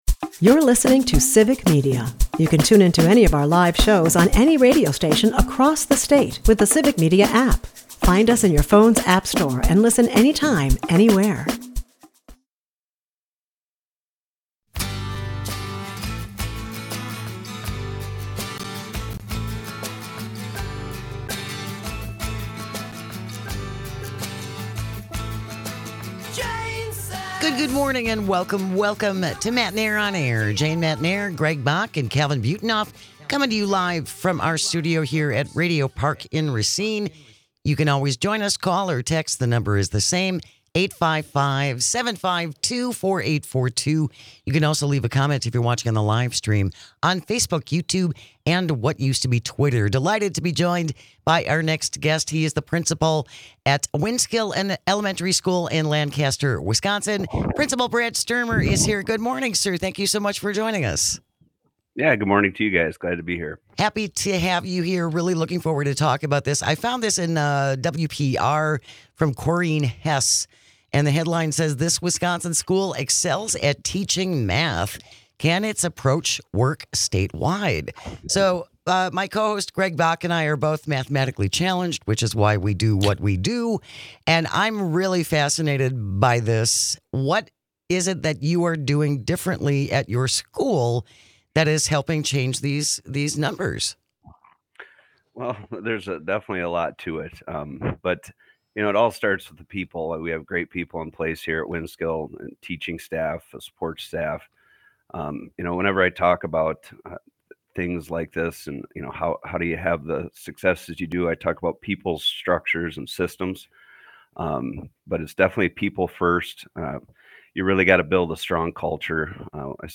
The weekend is here, so we have a curated best of episode with our favorite interviews and moments from this past week!